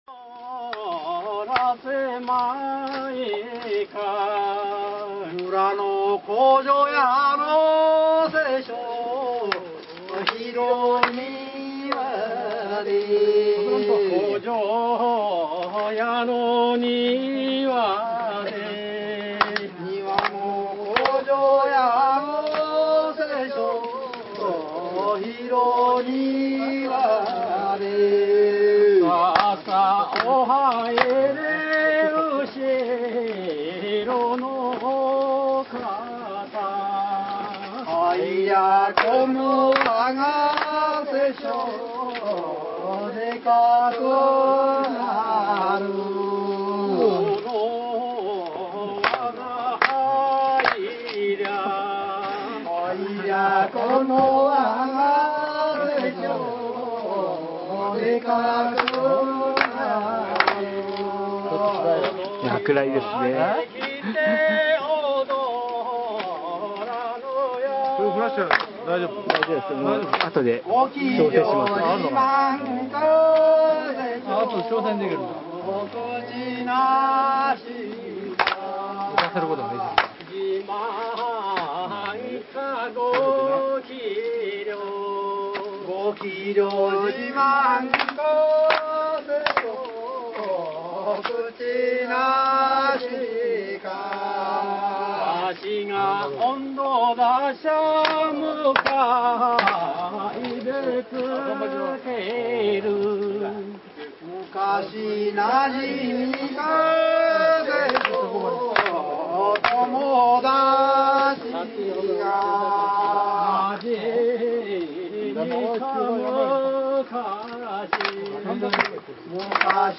場　　　所 ： 静岡県水窪町西浦地区新細
録音では、「おどらまいかよ～、おどらせまいか」と唄う音頭の歌や、踊り手との掛け合いの様子、特徴的な３拍の手拍子など、ほのぼのとした山里の盆踊りの音風景を聞きとることができます。
夜半、奇跡的に雨があがり、堂外の広場で念願の「セショウ」を取材することができました。
雨上がりの高原の夜空に吸い込まれるような歌声は、心にしみる感動的なものでした。